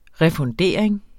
Udtale [ ʁεfɔnˈdeˀɐ̯eŋ ]